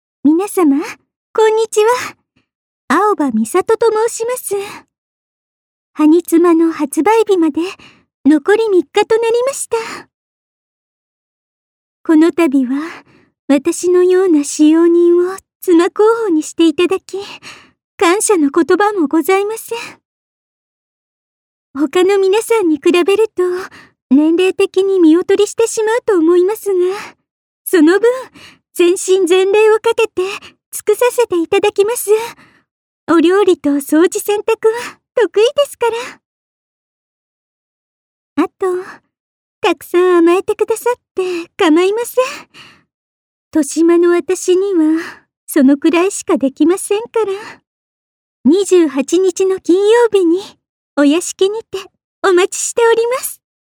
カウントダウンボイス公開！